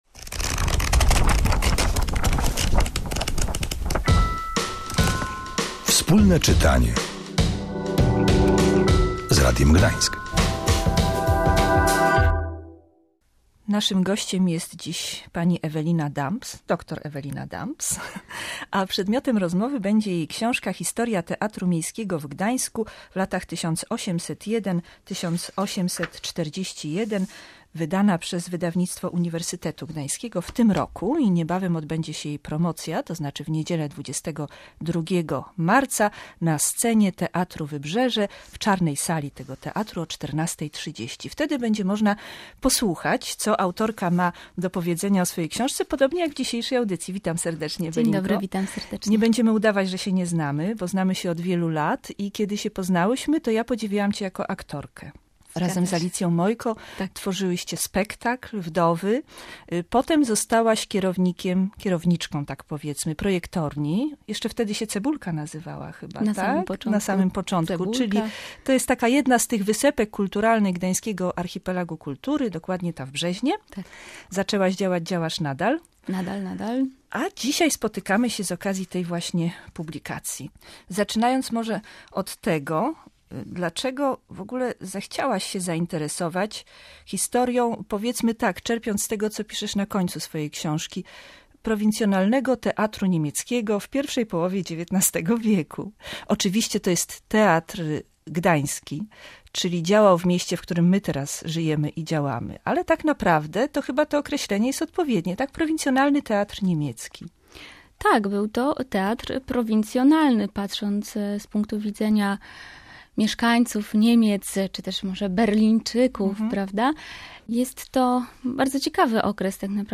Opowiedziała o nich także w audycji Wspólne czytanie z Radiem Gdańsk. Teatr Miejski (Stadttheater) został otwarty 3 sierpnia 1801 roku.